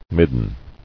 [mid·den]